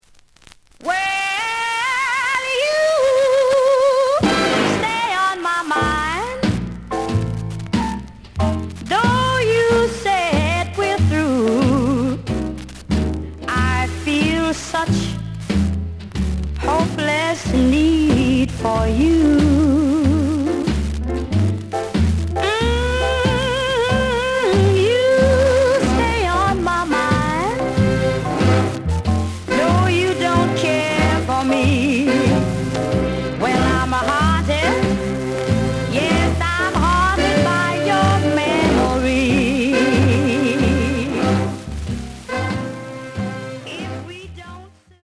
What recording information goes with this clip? The audio sample is recorded from the actual item. Noticeable noise on B side.